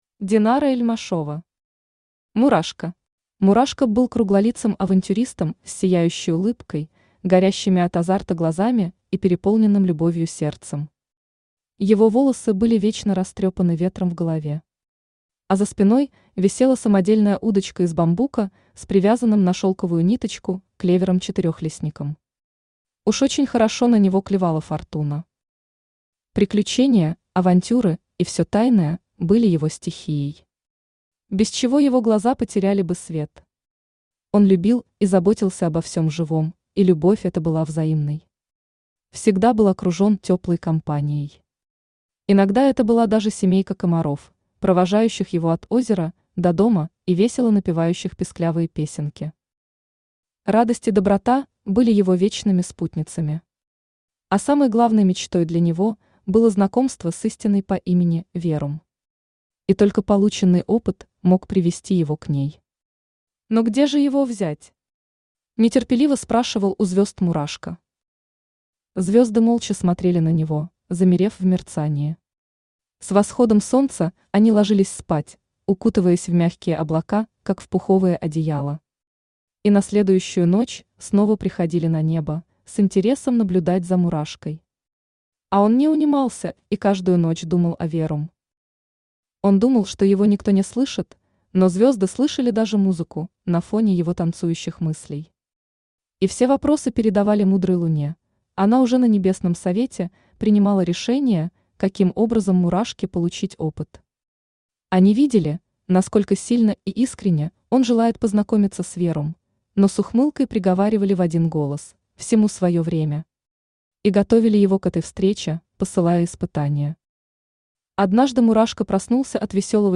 Аудиокнига Мурашка | Библиотека аудиокниг
Читает аудиокнигу Авточтец ЛитРес.